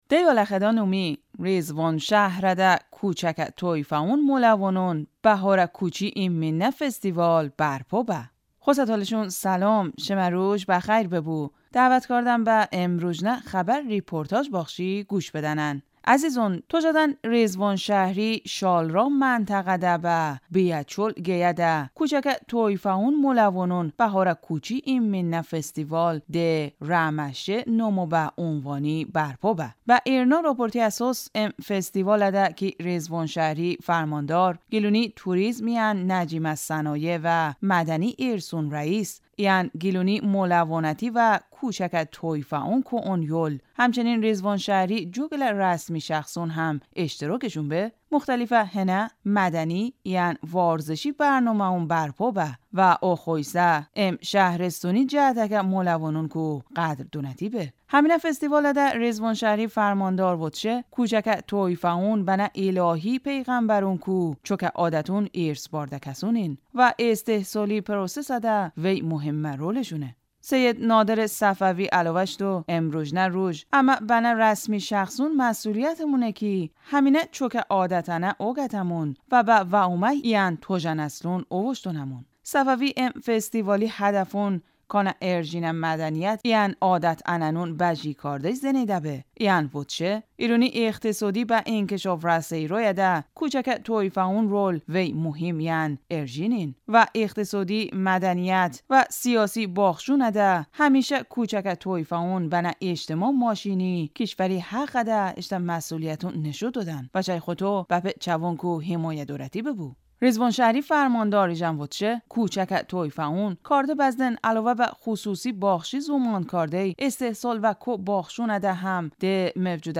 Xosə Tolışon səlom, şımə ruj bə xəy bıbu, dəvət kardəm bə ımrujnə xəbə reportaj baxşi quş bıdənən.